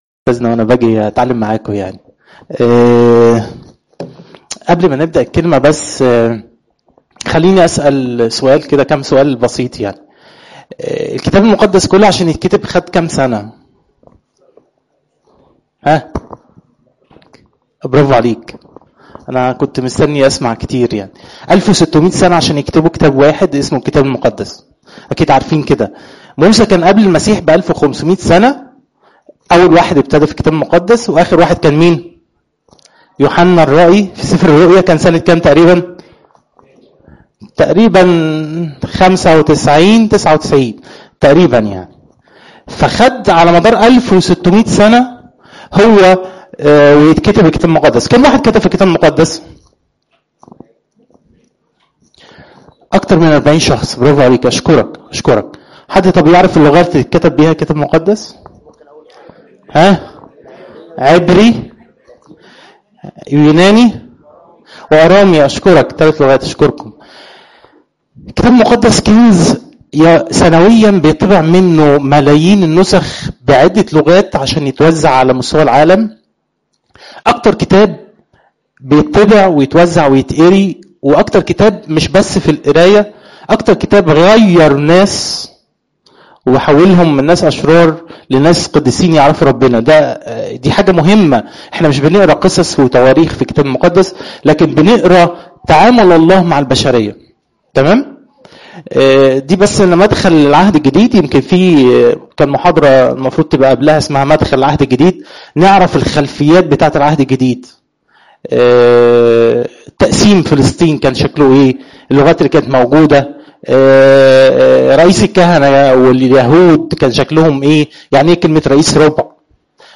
إجتماع الصخرة للشباب الخريجين